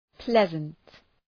{‘plezənt}